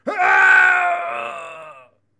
男人的呼噜声和尖叫声 " 男人的尖叫声1
描述：男人挣扎着
Tag: 呐喊 咕噜的声音